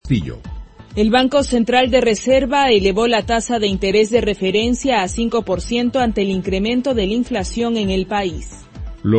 Titulares